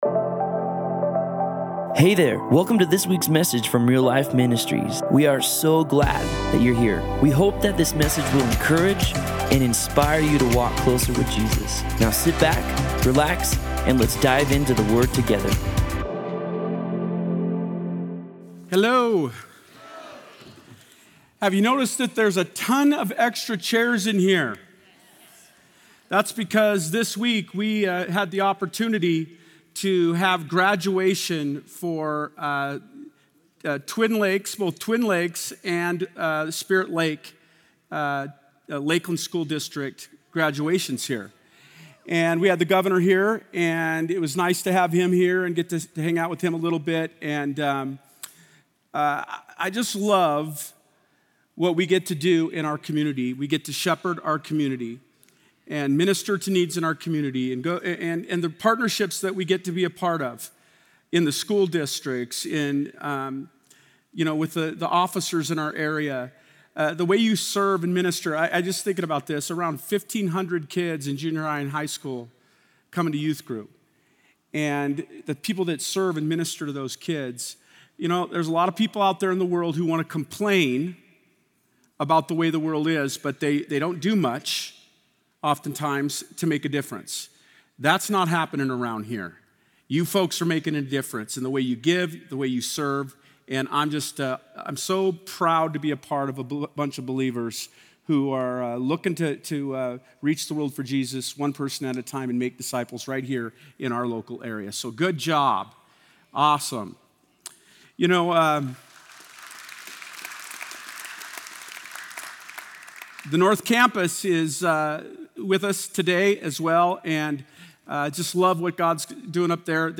Other Sermon in this Series